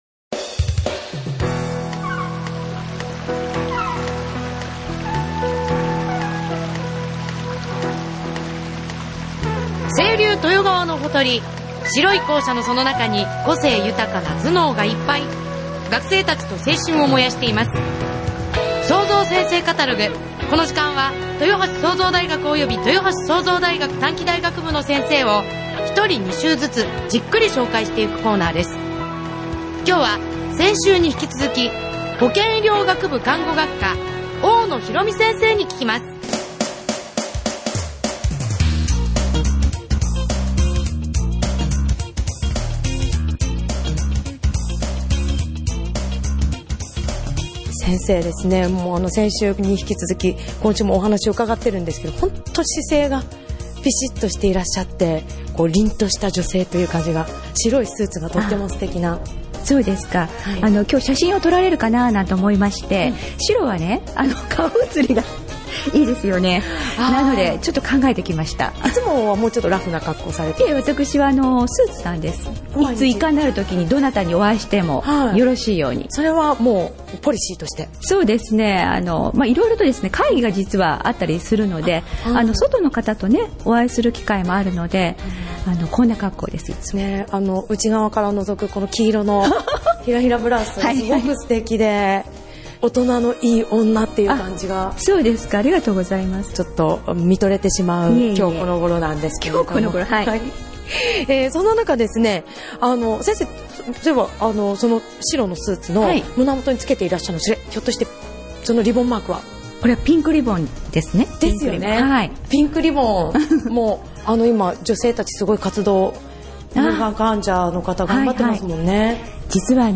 10月 17日 インタビュー前半 10月 24日 インタビュー後半 ※エフエム豊橋「SOZO’s先生カタログ」毎週月曜日 15：30より